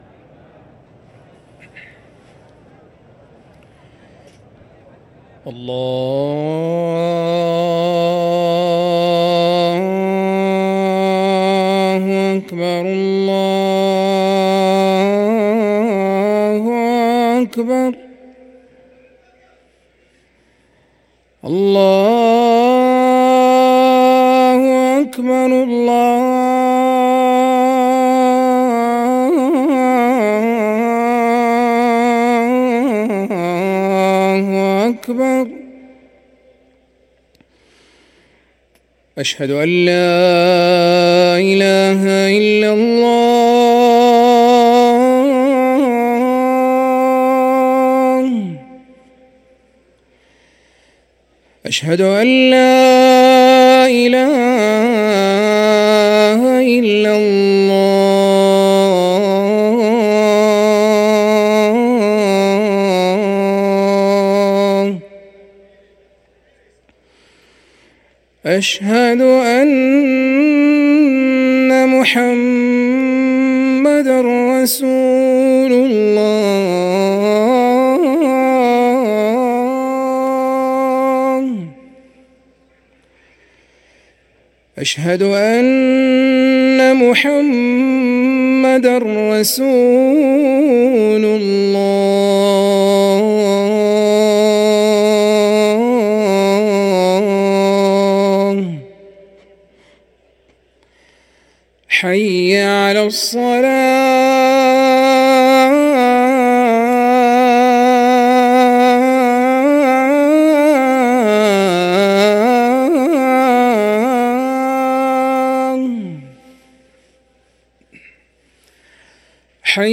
ركن الأذان 🕋